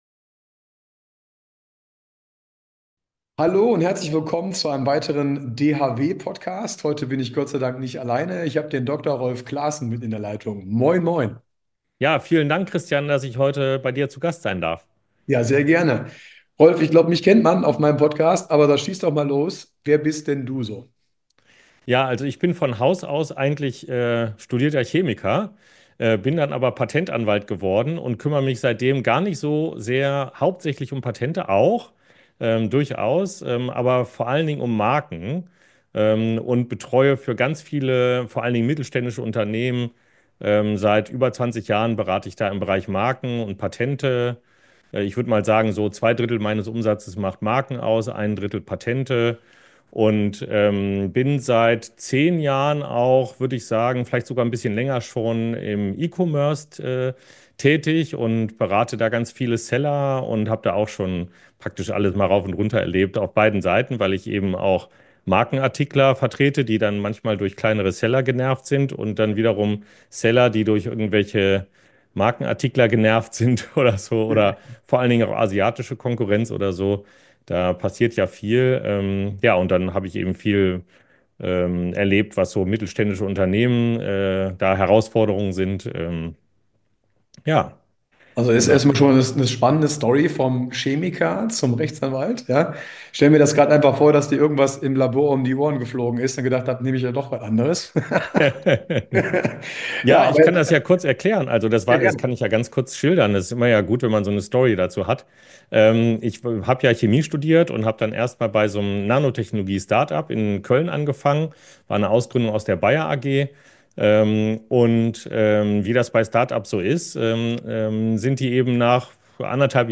Patent-Anwalt und Steuerberater - reden über E Commerce ~ DER DHW-PODCAST Podcast